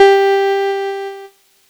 Cheese Note 01-G2.wav